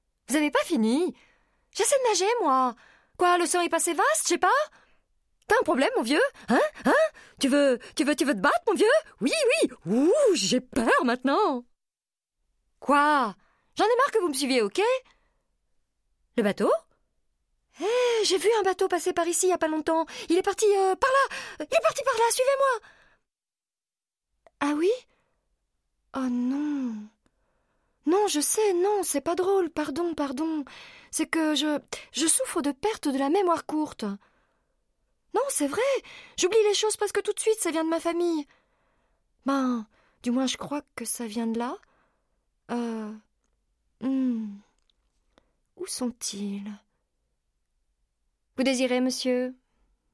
Bandes-son
Voix Doris dans NEMO
Comédienne
Doublage